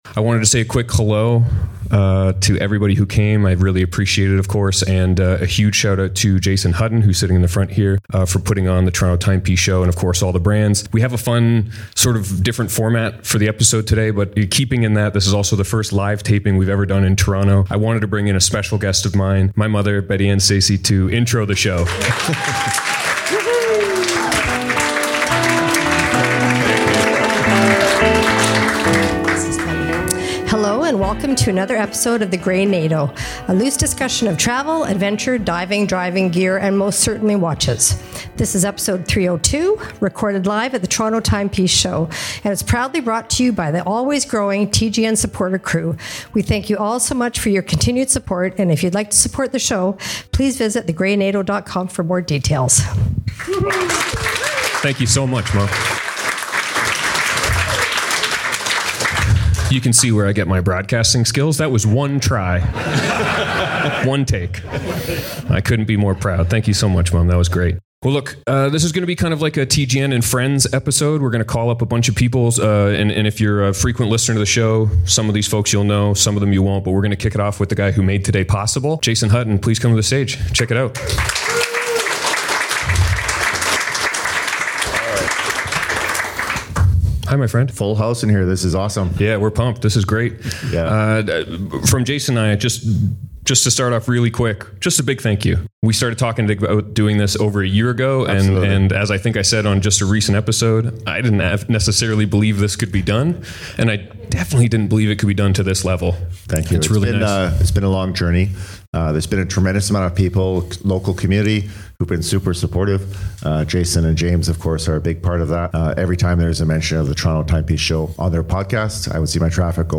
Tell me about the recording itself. This episode of The Grey Nato podcast was recorded live at the Toronto Timepiece Show.